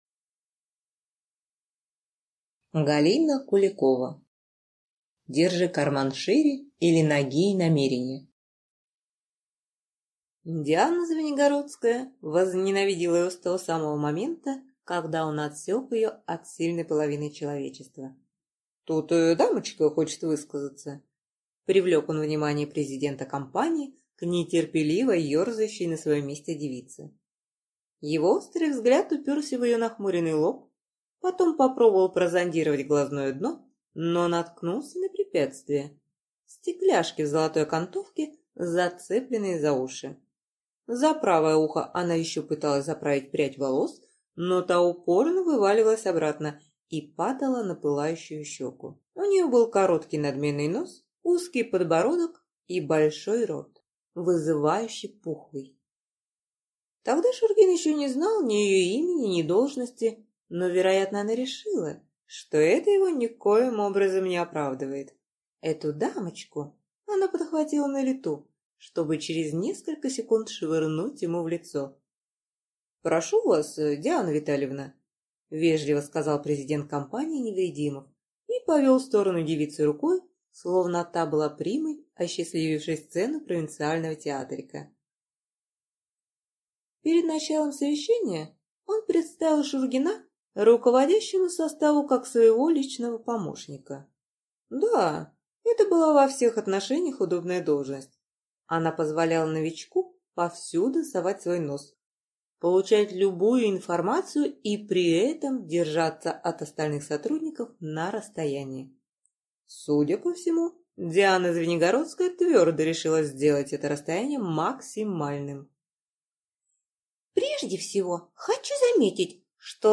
Аудиокнига Держи карман шире, или Нагие намерения | Библиотека аудиокниг
Прослушать и бесплатно скачать фрагмент аудиокниги